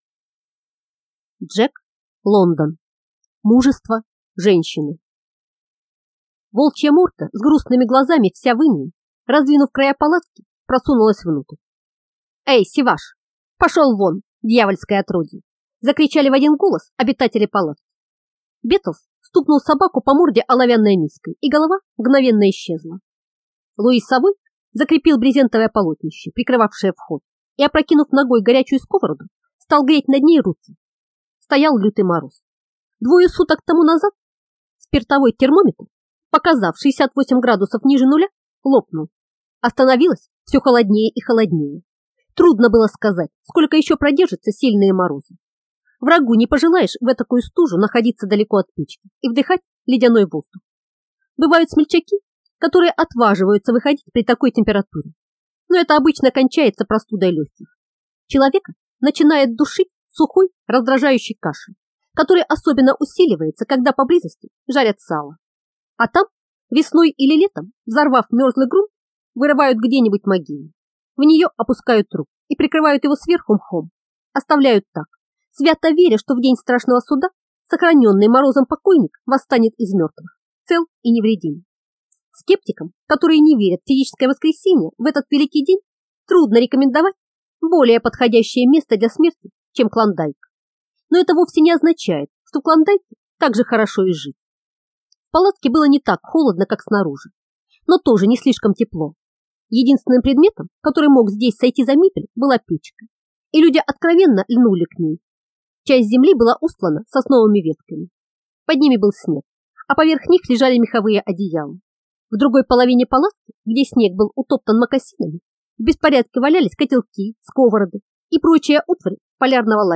Aудиокнига Мужество женщины